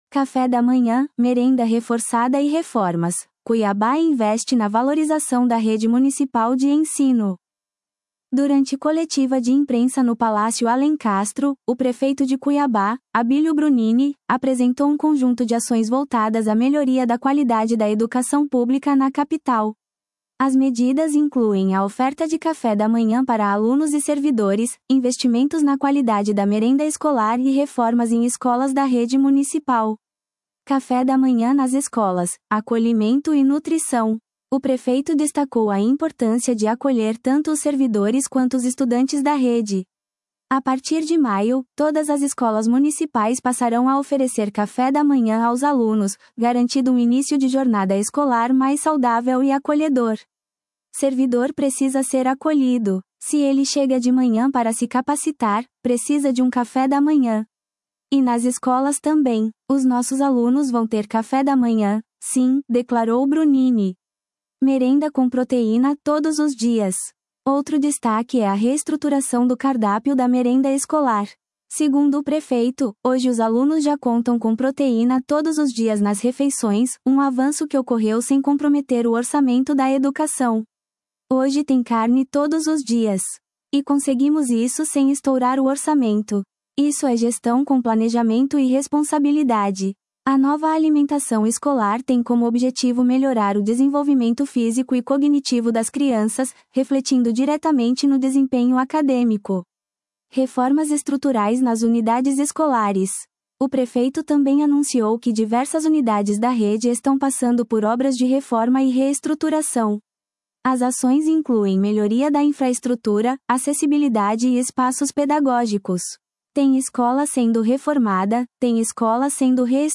Durante coletiva de imprensa no Palácio Alencastro, o prefeito de Cuiabá, Abílio Brunini, apresentou um conjunto de ações voltadas à melhoria da qualidade da educação pública na capital. As medidas incluem a oferta de café da manhã para alunos e servidores, investimentos na qualidade da merenda escolar e reformas em escolas da rede municipal.